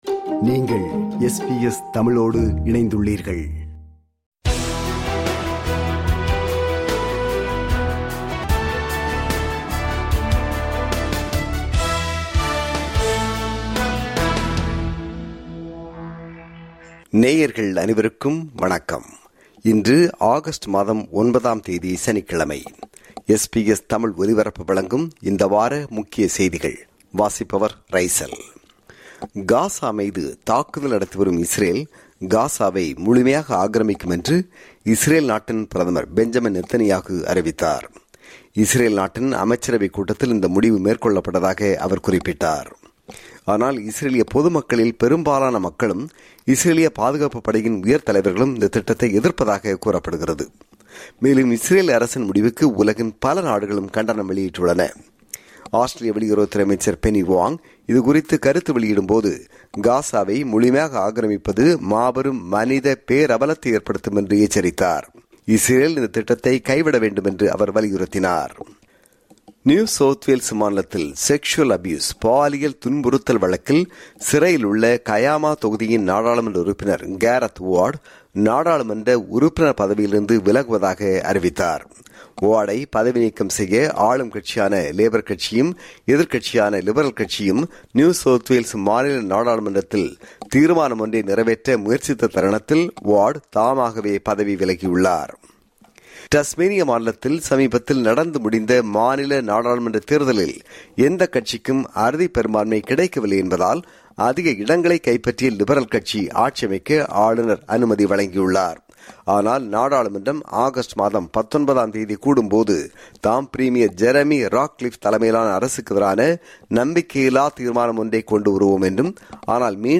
இந்த வாரத்தின் ஆஸ்திரேலிய, உலக செய்திகளின் தொகுப்பு